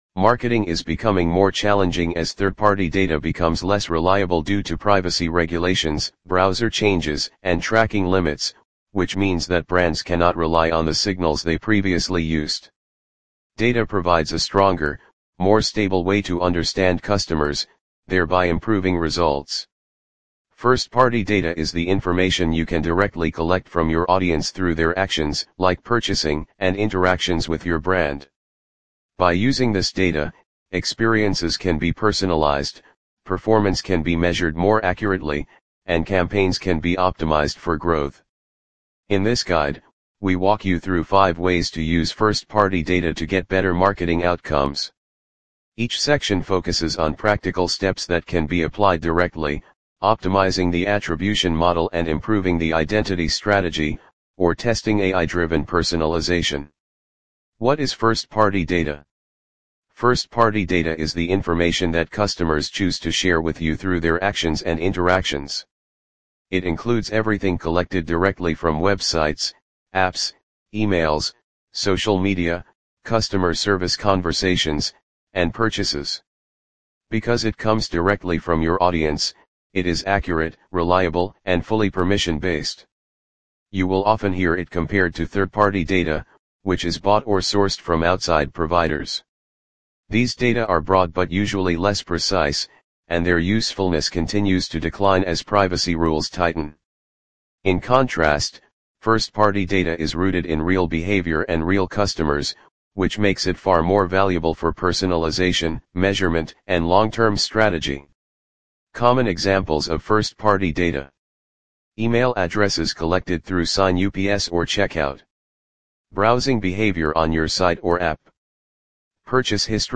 Welcome-to-TextAloud-3.mp3